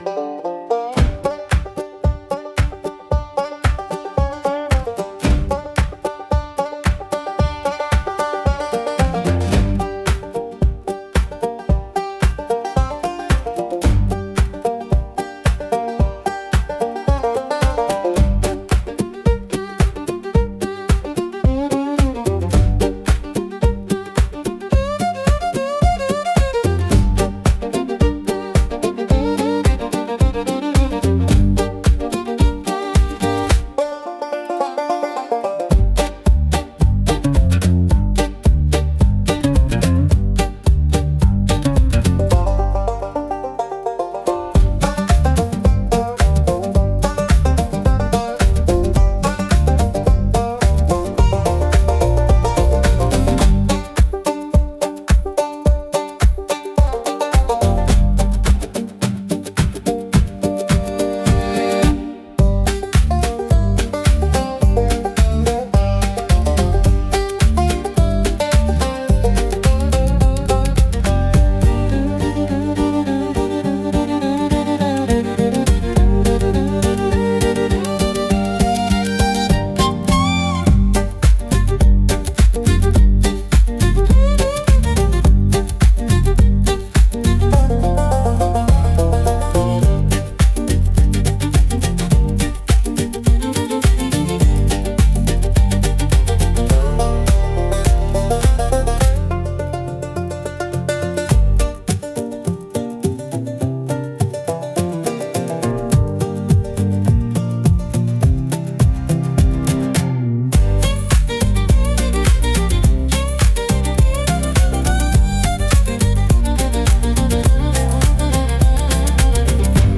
イメージ：インスト,オールドタイム・ブルーグラス,ブルーグラス・フォーク,1930年代アパラチア民謡,ボンジョー
インストゥルメンタル（instrumental）